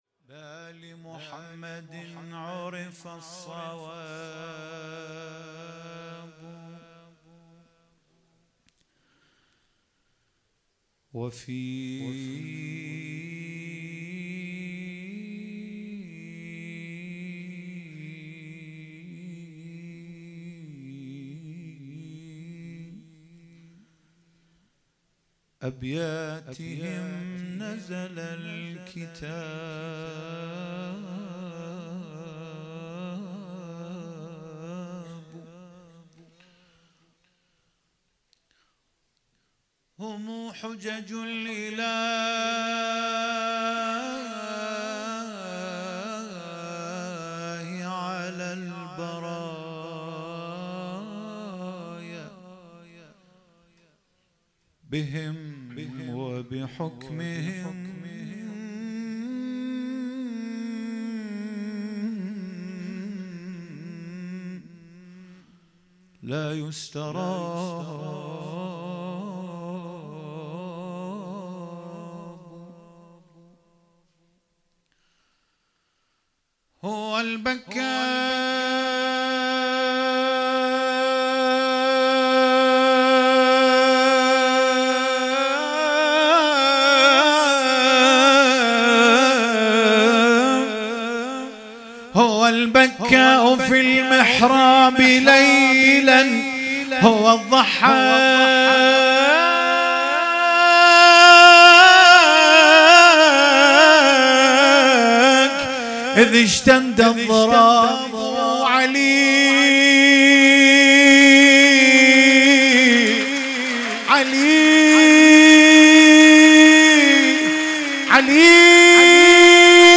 هیئت بین الحرمین
شعرخوانی